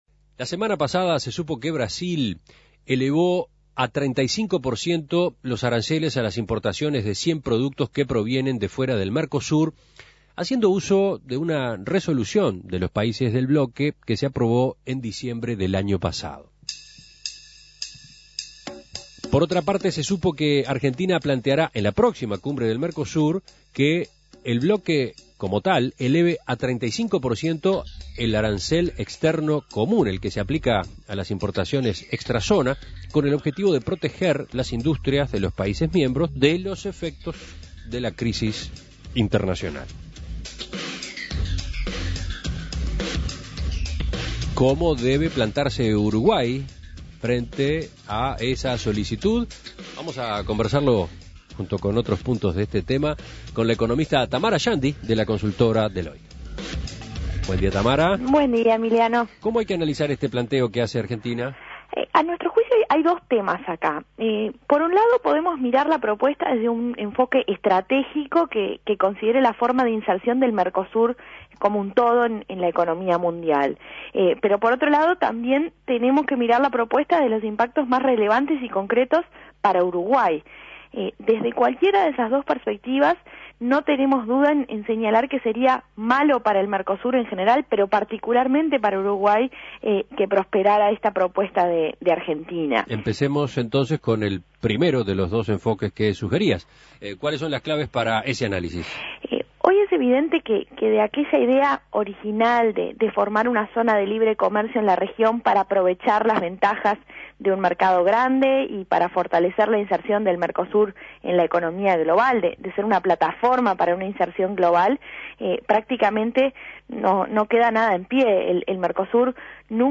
Análisis Económico Argentina planteará a los socios del Mercosur un nuevo aumento del arancel externo común: ¿cómo se debe parar Uruguay?